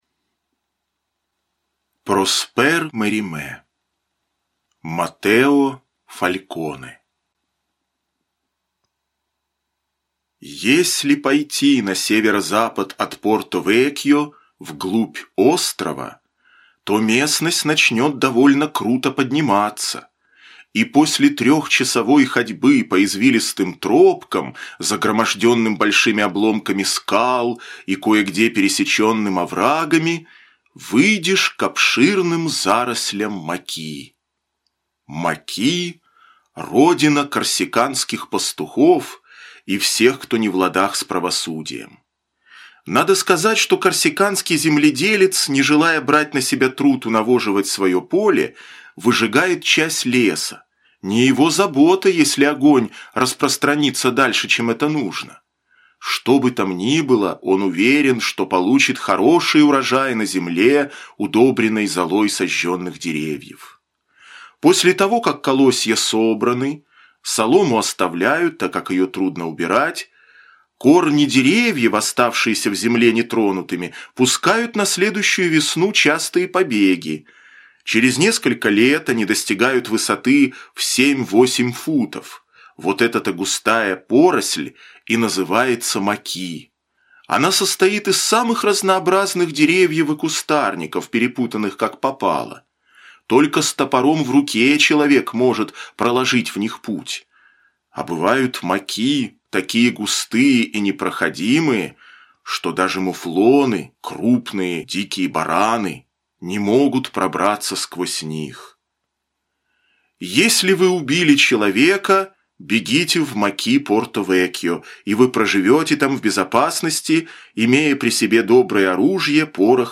Маттео Фальконе - аудио новелла Мериме - слушать онлайн